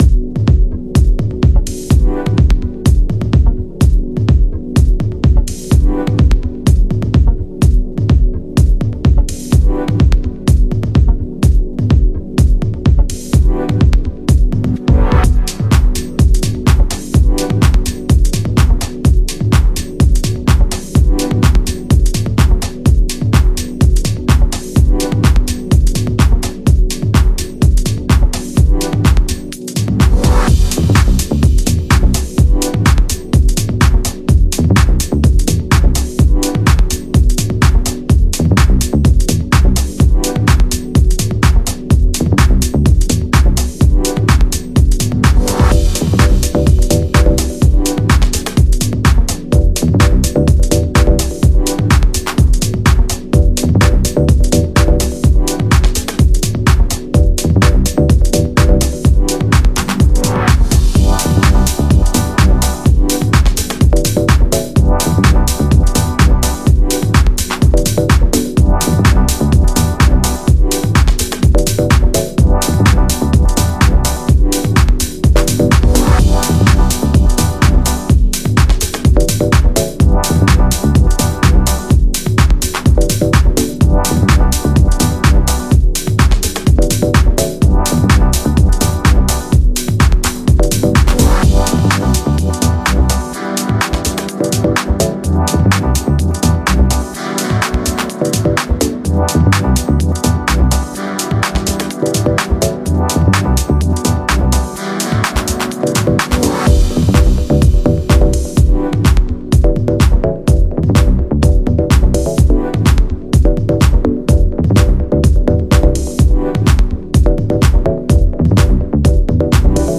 Genre: Minimal / Deep Tech